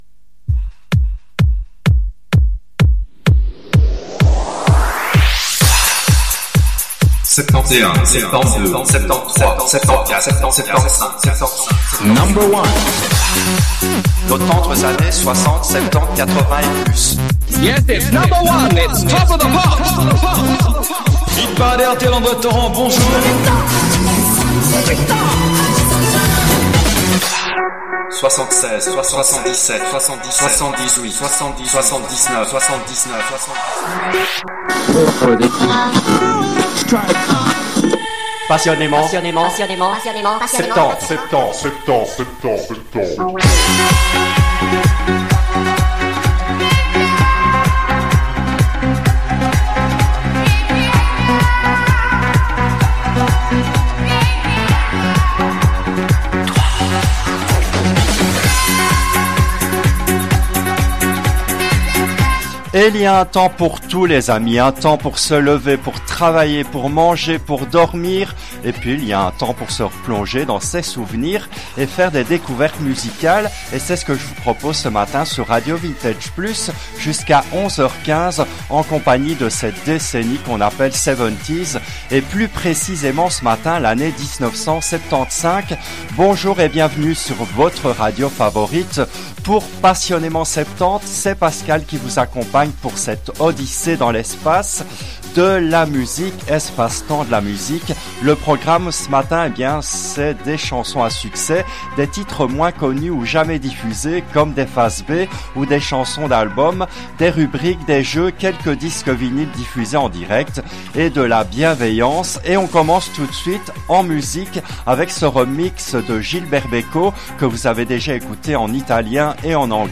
Elle a été diffusée en direct le jeudi 08 février 2024 à 10h depuis les studios belges de RADIO VINTAGE PLUS.